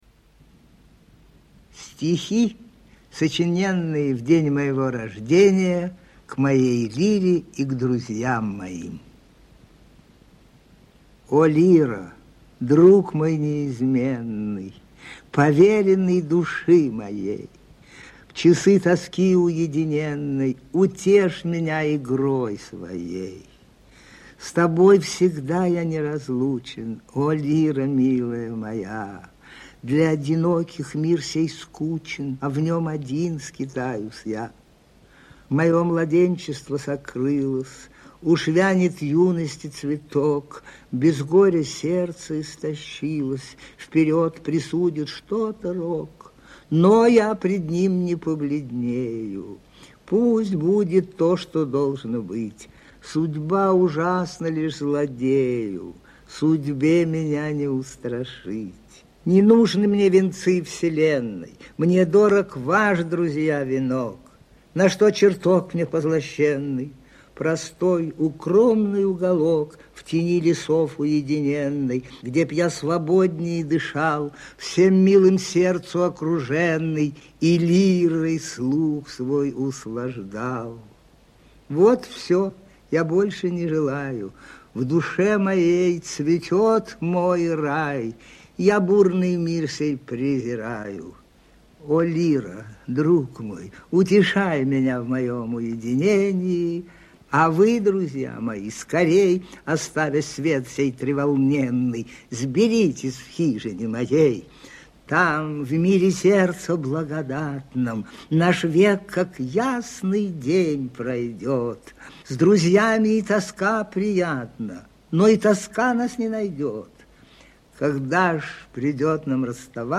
1. «Исп. Дмитрий Журавлев – В.Жуковский – Стихи, сочиненные в день моего рождения» /